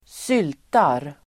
Uttal: [²s'yl:tar]